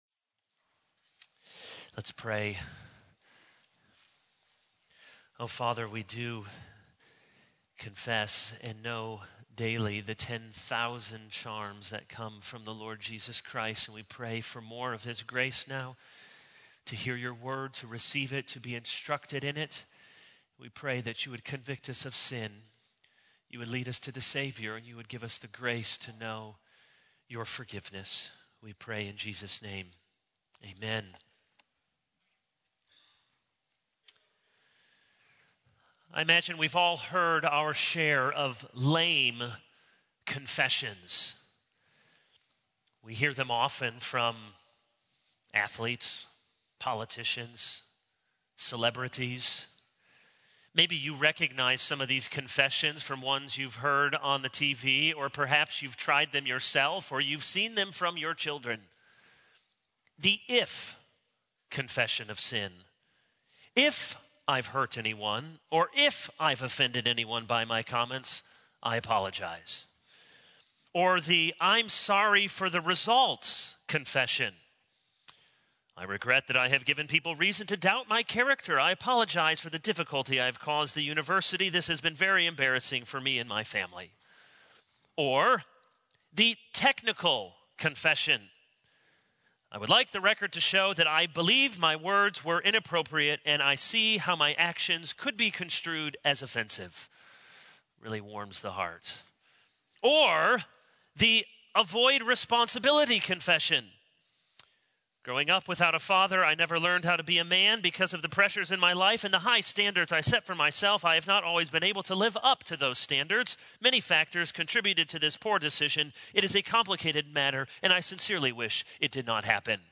All Sermons A Royal Coward 0:00 / Download Copied!